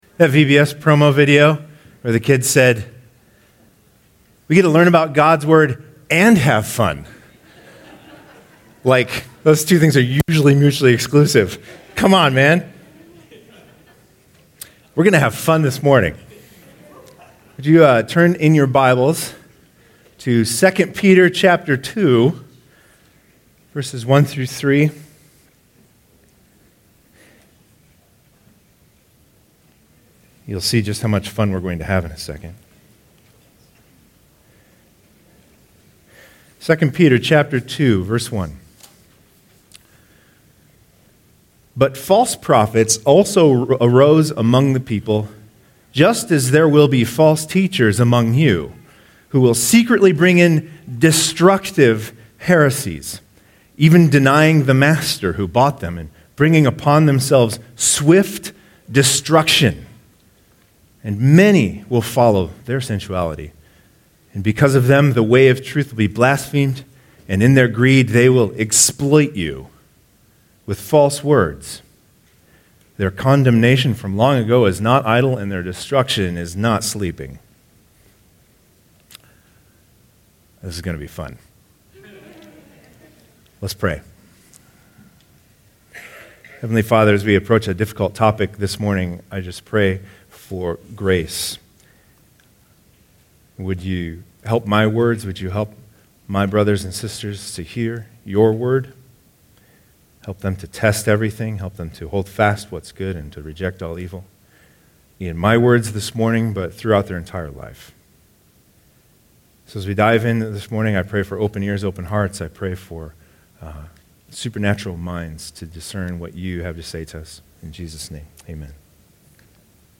2018 Stay up to date with “ Stonebrook Church Sermons Podcast ”